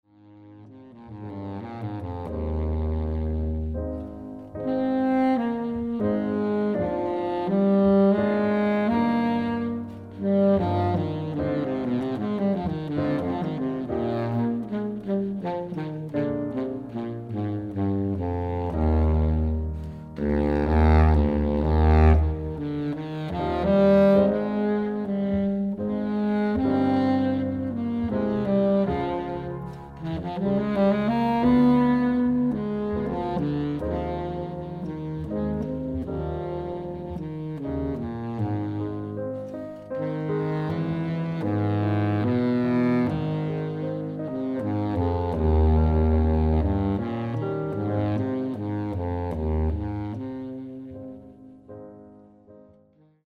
baritone saxophone and piano